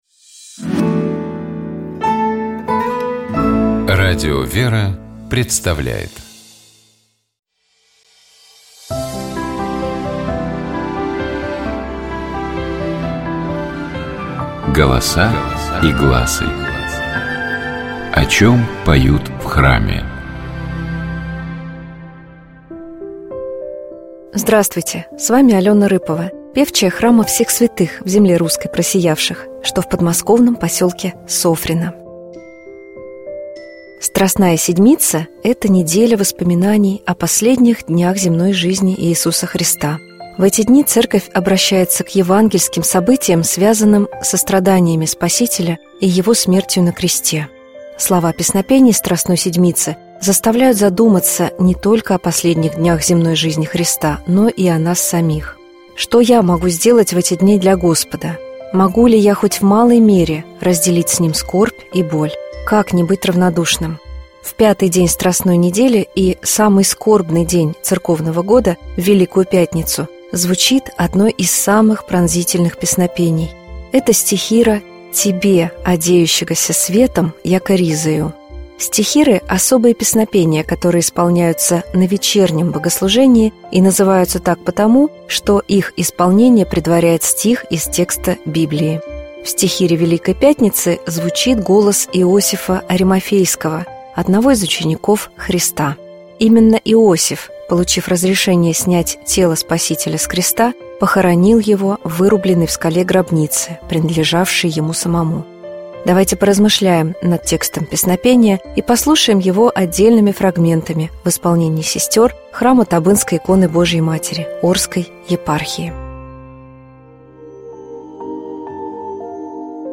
Стихиры — особые песнопения, которые исполняются на вечернем богослужении и называются так потому, что их исполнение предваряет стих из текста Библии. В стихире Великой Пятницы звучит голос Иосифа Аримафейского — одного из учеников Христа.
Давайте послушаем стихиру Великой Пятницы полностью в исполнении сестёр храма Табынской иконы Божией Матери Орской епархии.
Golosa-i-glasy-Tebe-odejushhagosja-svetom-jako-rizoju-stihira-Velikoj-Pjatnicy.mp3